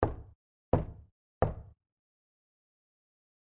敲击声" 敲击声3倍慢
描述：敲击木桌的变化